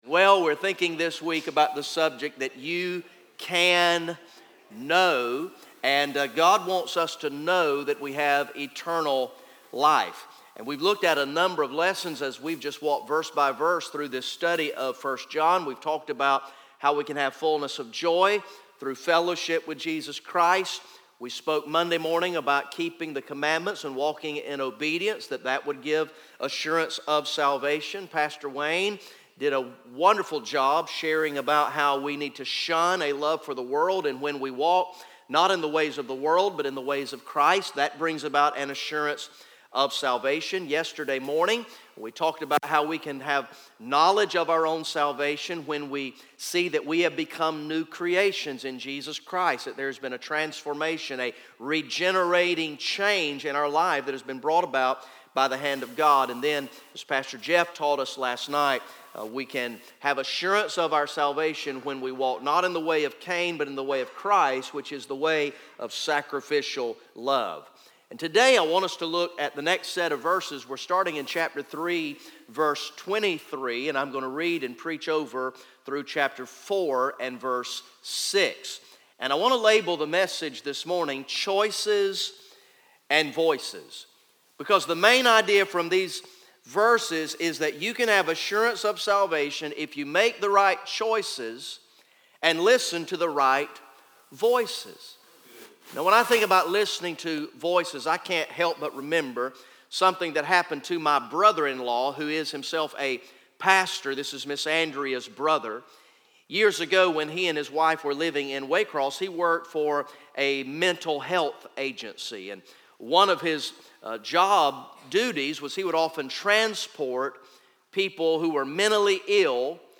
Message #07 from the ESM Summer Camp sermon series through the book of First John entitled "You Can Know"